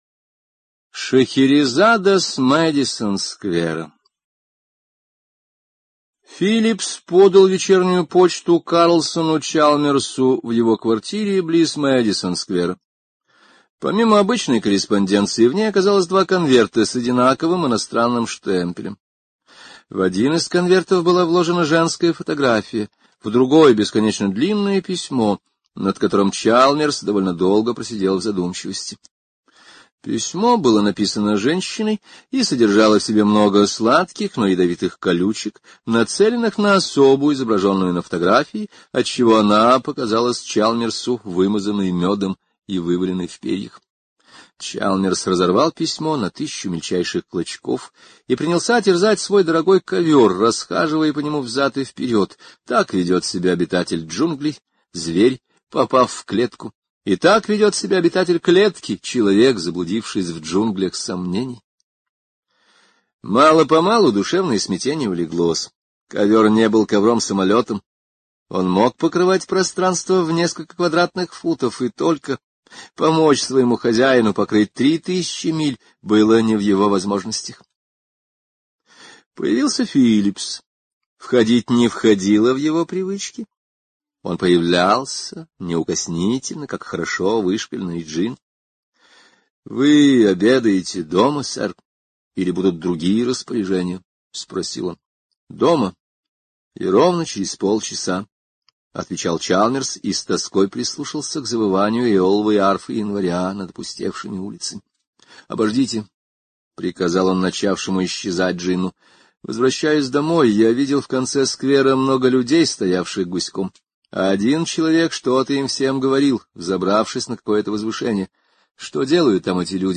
Шехерезада с Мэдисон-сквера — слушать аудиосказку Генри О бесплатно онлайн